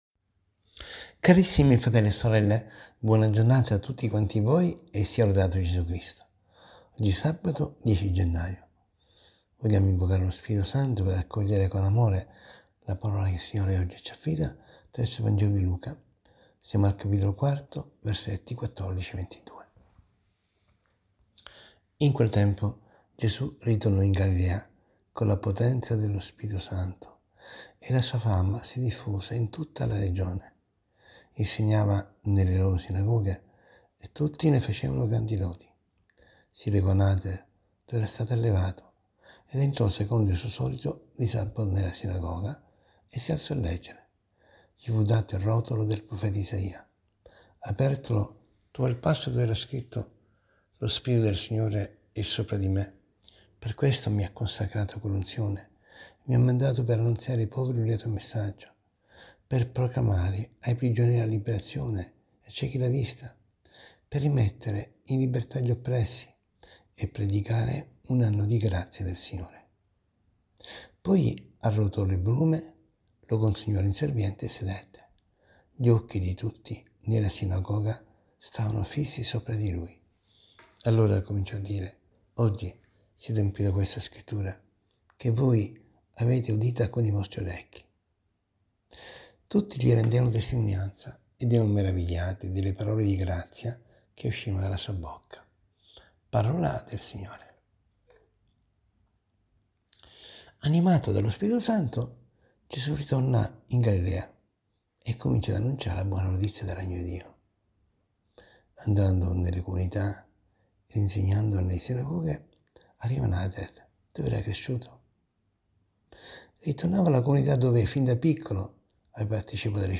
ASCOLTA  RIFLESSIONE SULLA PAROLA DI DIO, - SE L'AUDIO NON PARTE CLICCA QUI